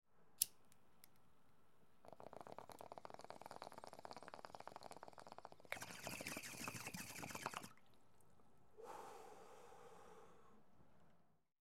Звуки бонга